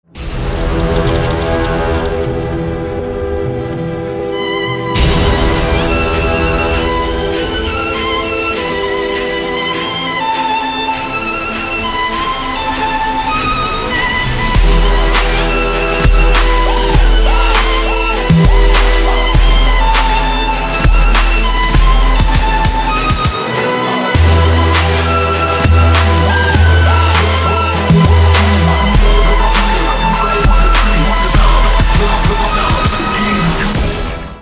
• Качество: 320, Stereo
громкие
dance
EDM
электронная музыка
скрипка
Trap